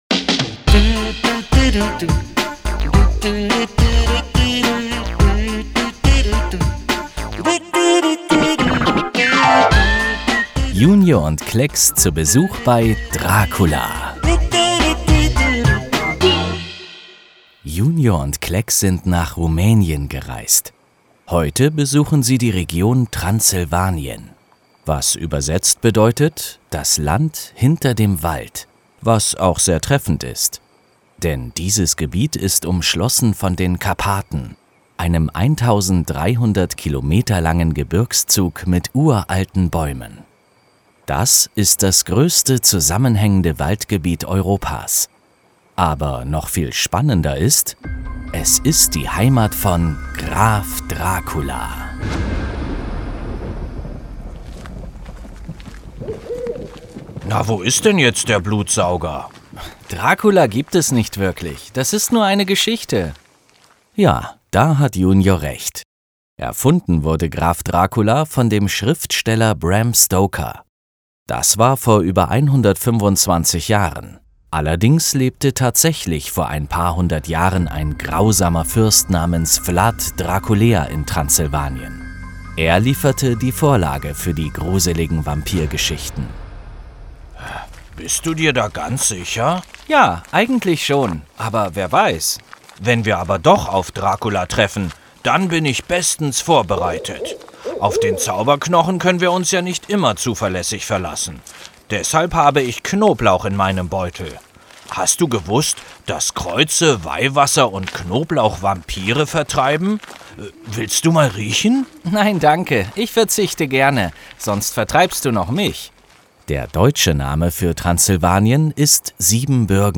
Hörspiel - JUNIOR Schweiz